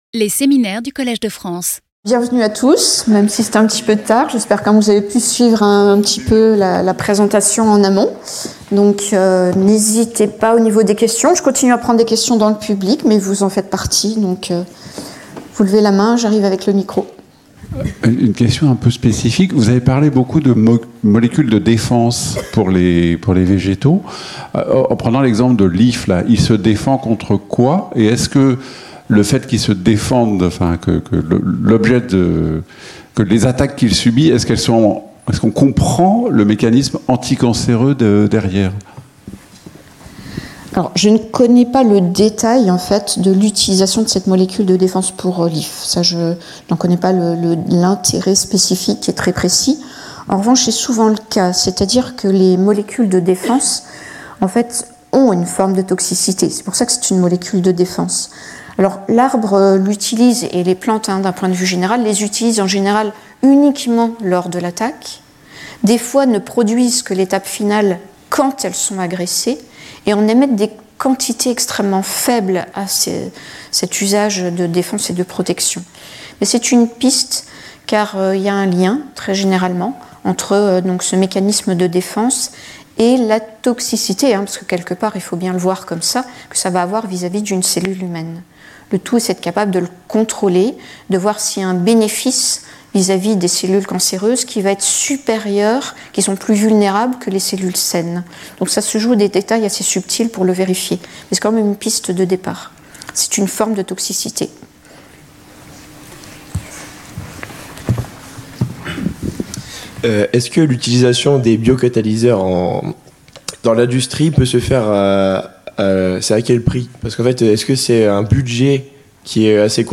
Dialogue avec de jeunes lycéens de Laon | Collège de France
Séminaire